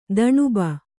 ♪ daṇuba